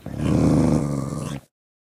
sounds / mob / wolf / growl1.mp3
growl1.mp3